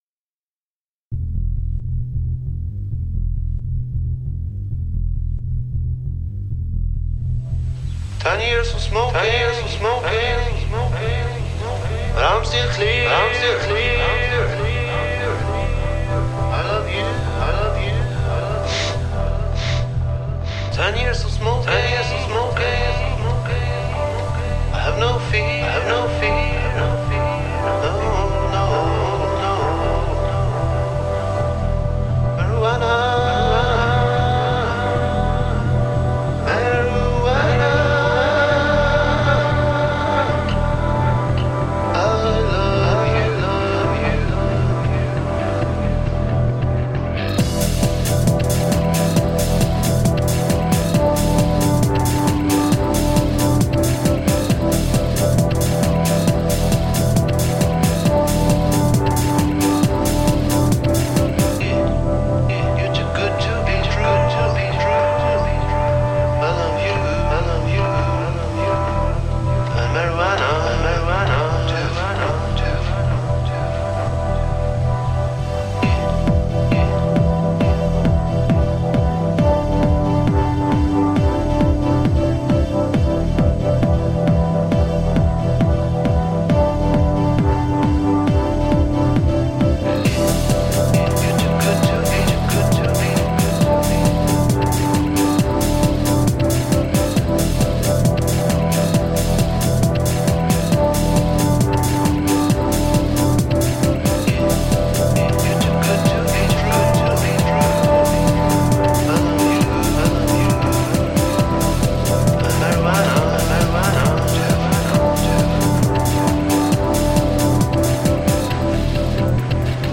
A wicked mix of ambient/jungle/trash trance.
Tagged as: Electronica, Electro Rock, Hard Electronic